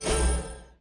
Media:kitsune_witch_atk_02.wav 攻击音效 atk 局内攻击音效
Kitsune_witch_atk_02.wav